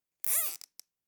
household
Zip Ties Secure 8